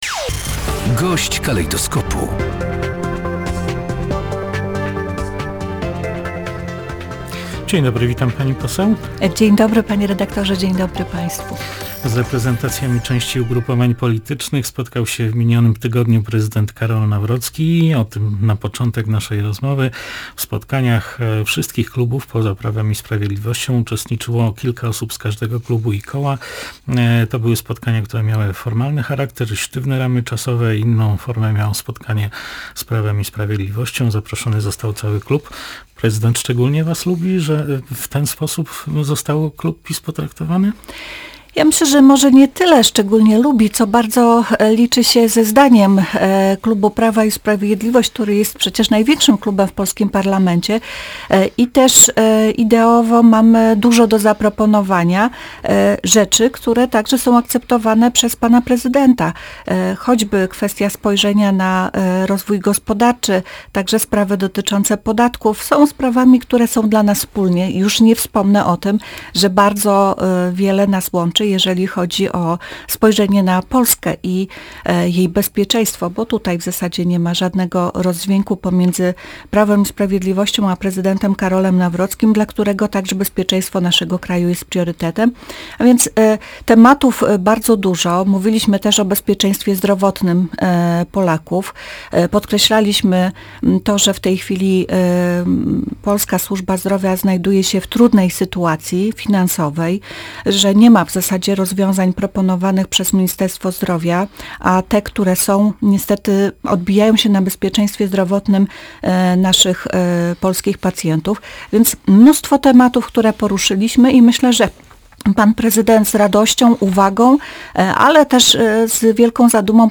Gość dnia • Prezydent RP liczy się ze zdaniem Prawa i Sprawiedliwości, bo to największe ugrupowanie w parlamencie - tak poseł PIS Ewa Leniart skomentowała na naszej antenie ostatnie spotkanie klubu tej partii z Karolem Nawrockim.